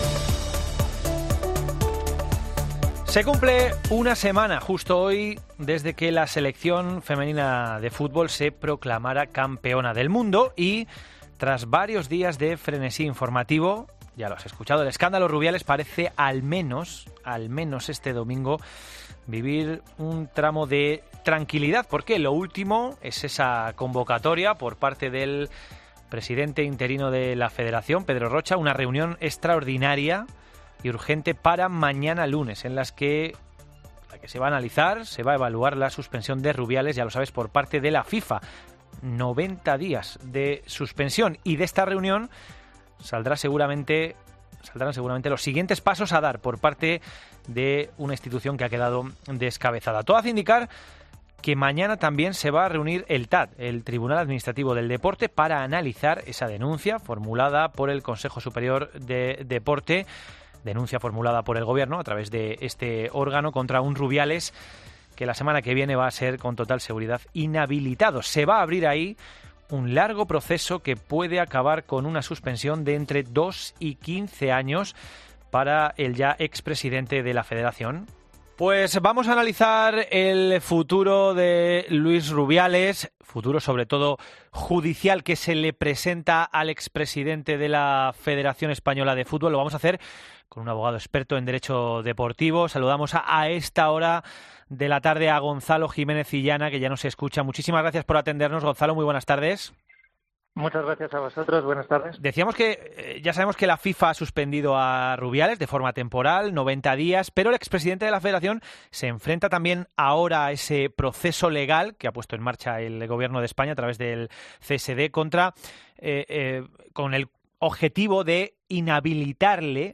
Abogado deportivo, en COPE: "Rubiales tiene en su mano recurrir a través del portal jurídico de la FIFA"